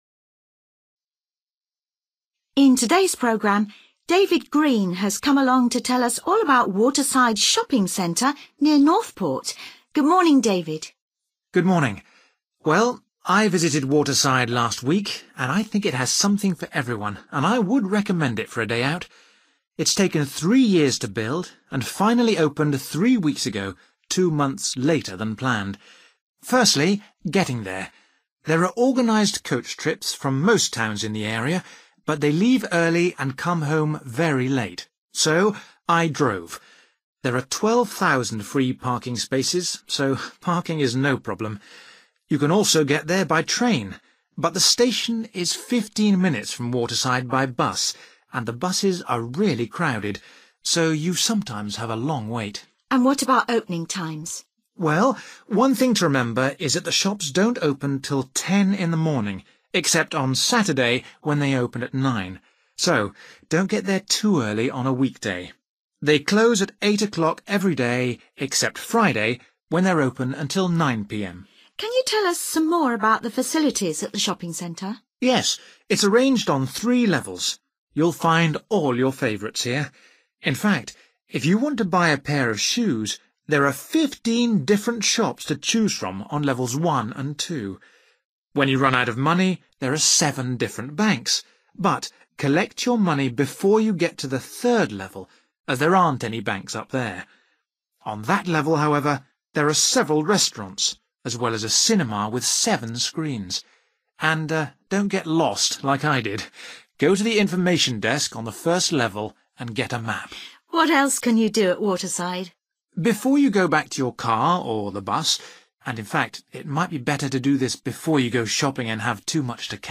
You will hear part of a local radio programme in which someone is talking about a shopping centre.